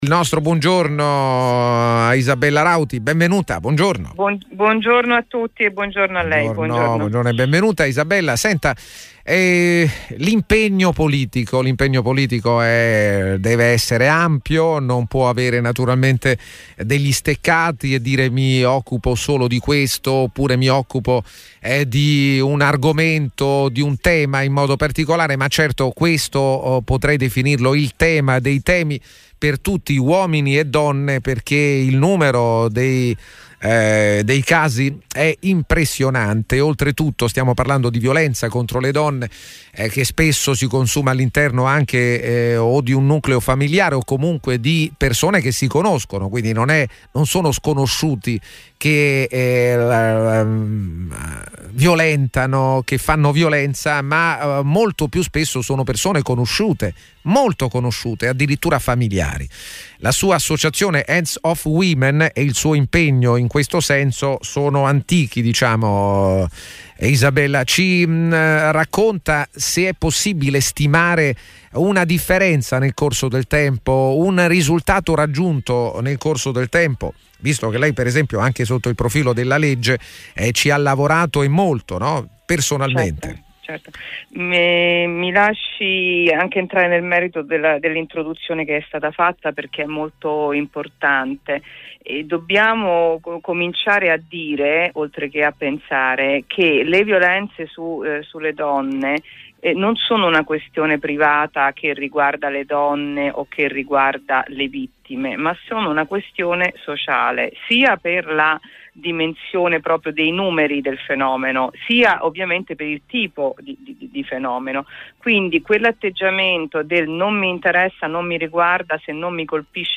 Tele Radio Più – Intervista del 3 giugno ai microfoni